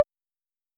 S 78_Tom1.wav